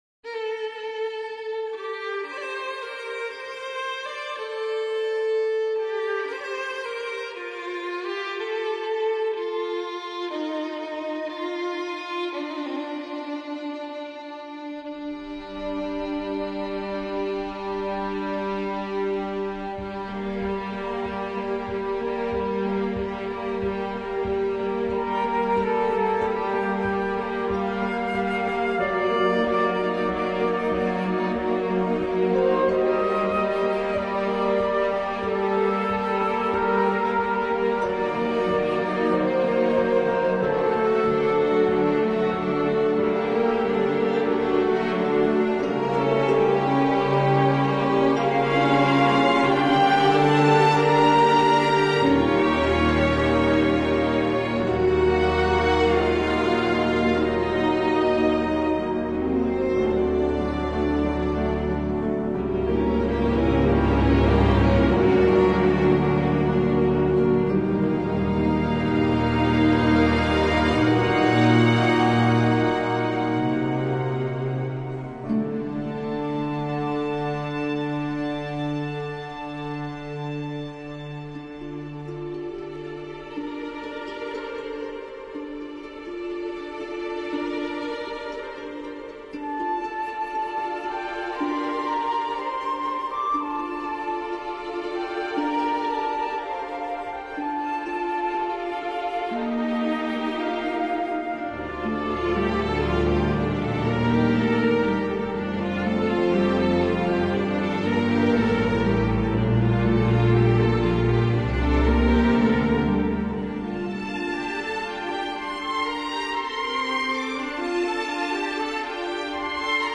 Avant-jazz